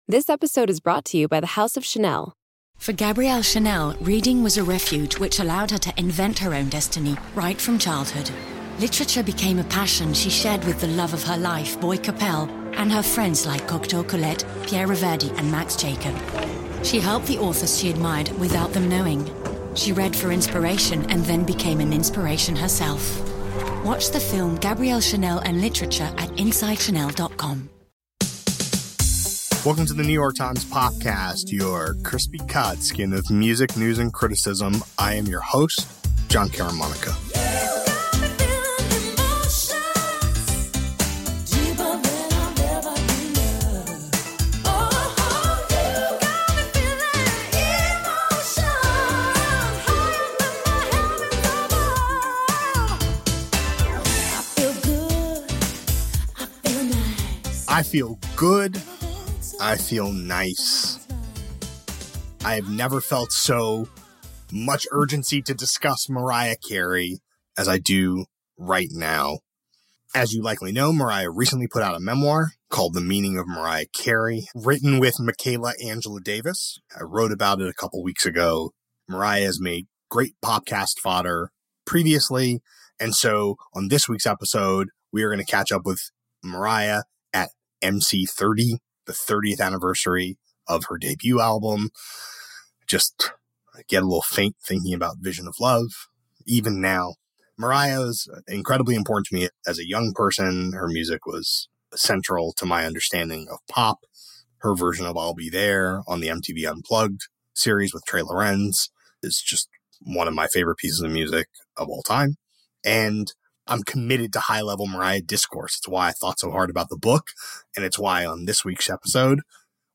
A conversation about her memoir, her range of contributions to pop music and her secret alt-rock album.